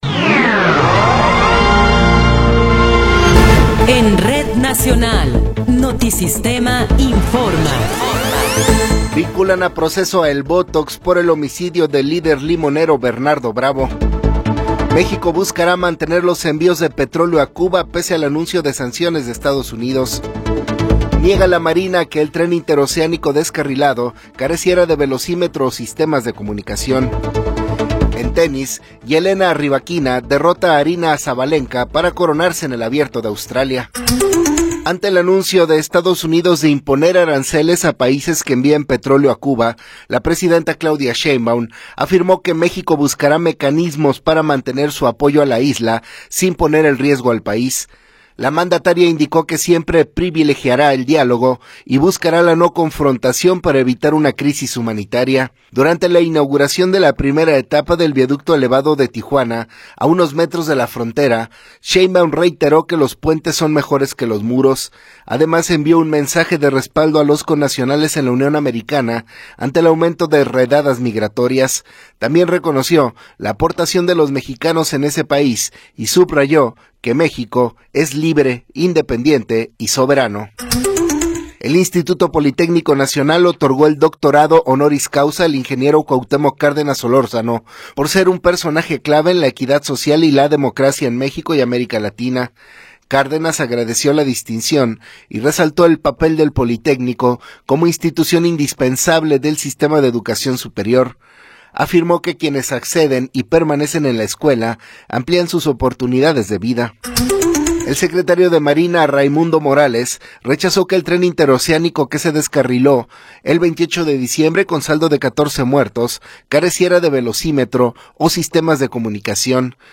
Noticiero 8 hrs. – 31 de Enero de 2026
Resumen informativo Notisistema, la mejor y más completa información cada hora en la hora.